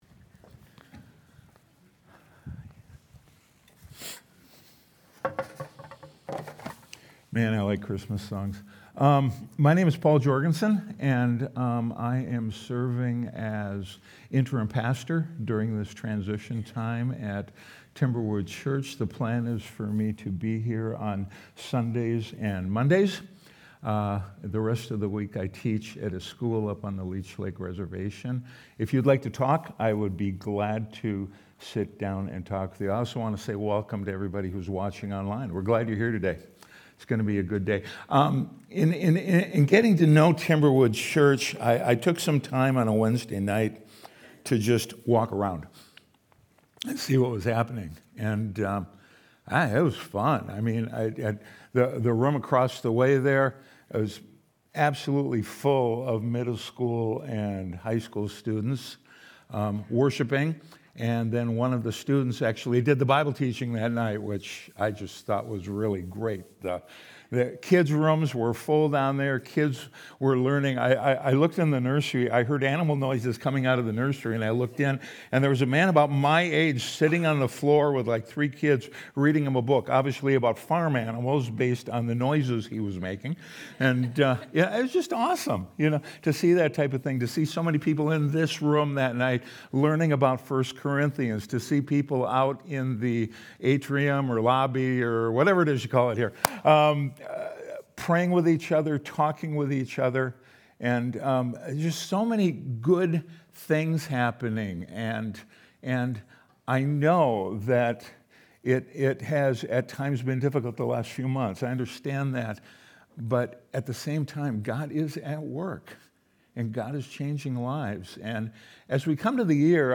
Sunday Sermon: 12-21-25